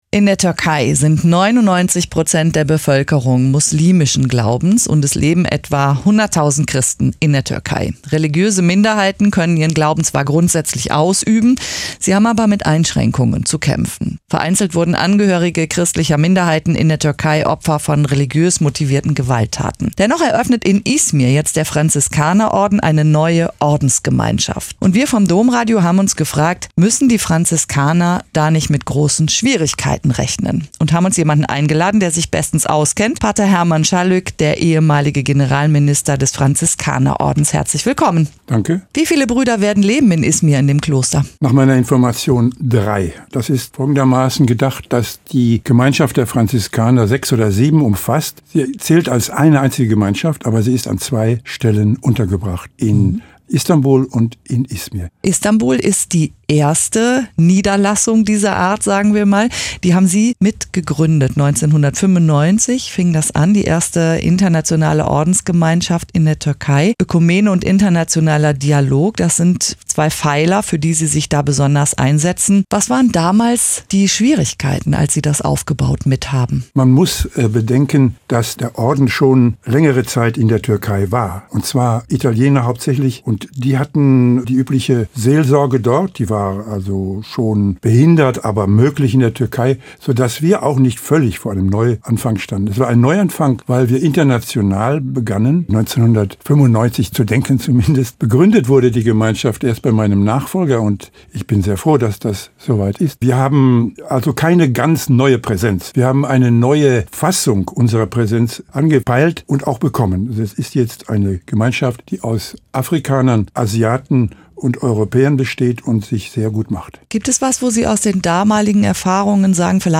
• Lieber hören statt lesen? Den folgenden Text des Interviews können Sie hier als Audiodatei anhören.